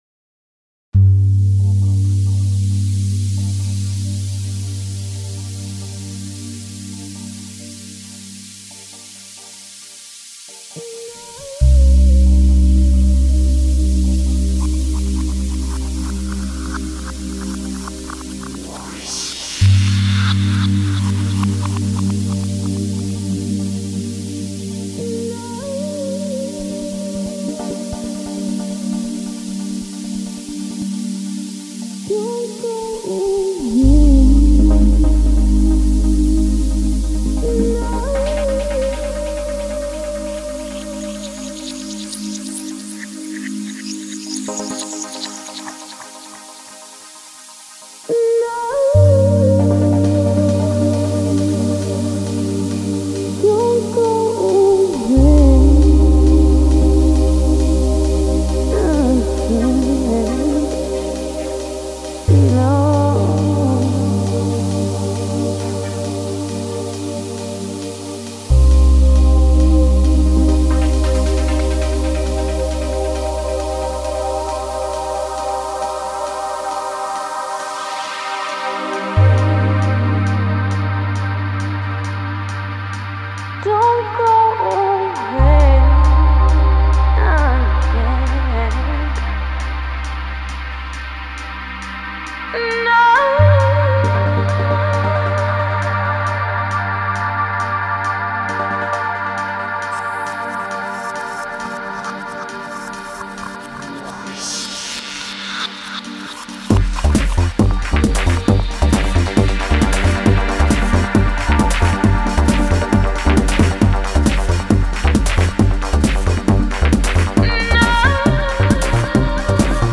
Electronic dance music